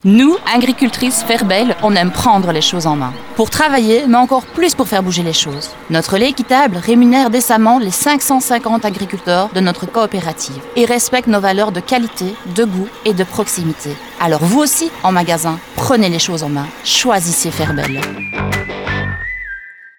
Ce sont leurs mains et même leur voix que l’on retrouve en presse quotidienne, presse magazine, print et digital, en radio ou encore en POS.